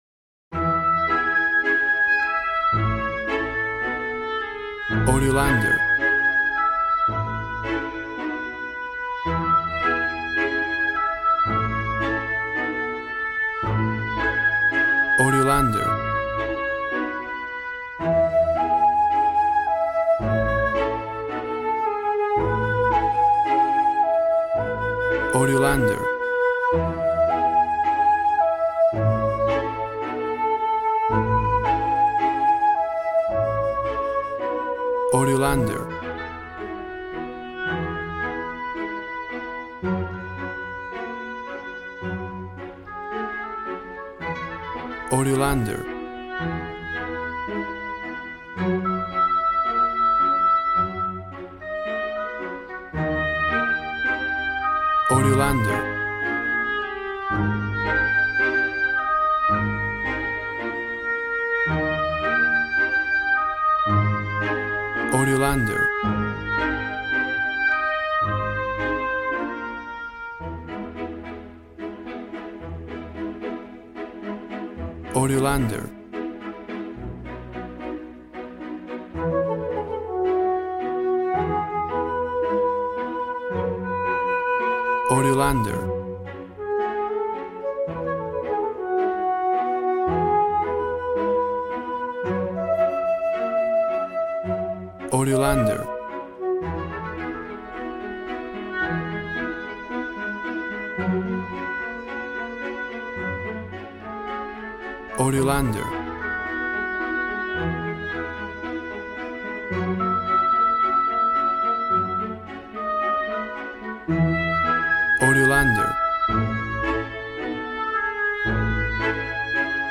A warm and stunning piece of playful classical music.
Regal and romantic, a classy piece of classical music.
Tempo (BPM) 110